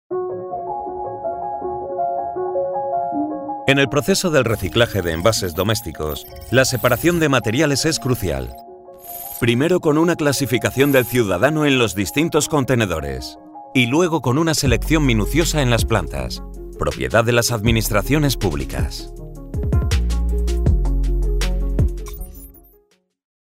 Vídeos explicativos
Soy locutor y actor de doblaje en España.
Neumann Tlm 103